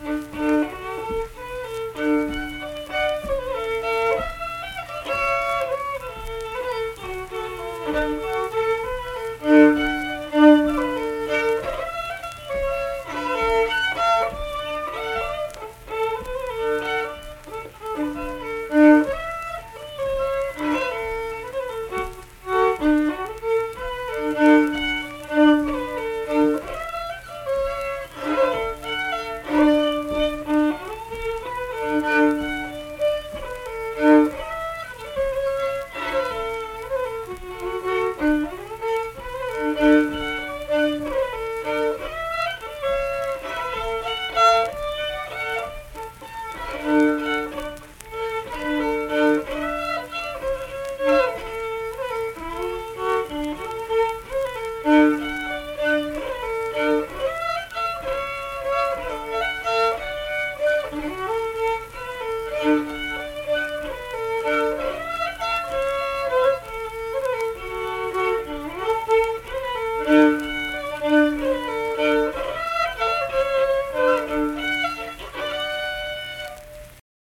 Unaccompanied fiddle performance
Instrumental Music
Fiddle
Tyler County (W. Va.), Middlebourne (W. Va.)